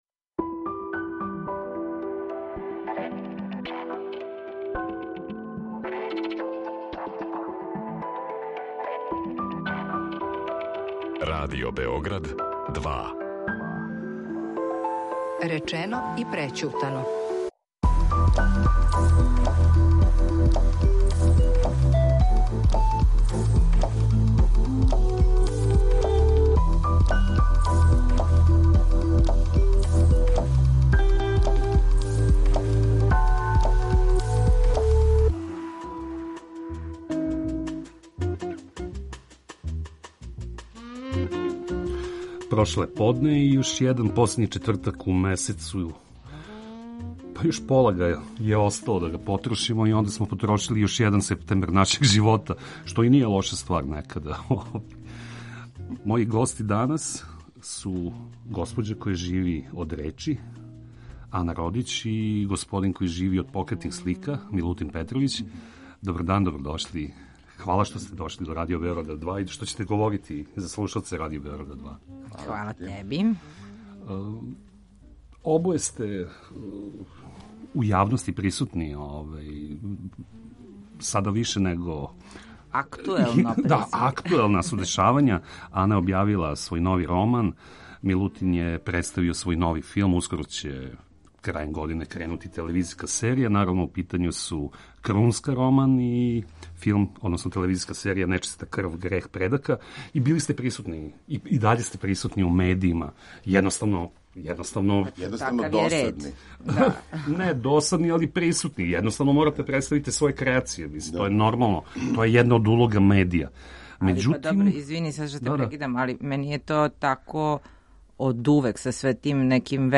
Гости емисије данас говоре о притиску медија на приватност јавних личности. Имамо ли права да заштитимо своју интиму, како то урадити? Да ли је могуће изоловати се од таблоидног „информисања" јавности?